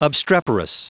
Prononciation du mot obstreperous en anglais (fichier audio)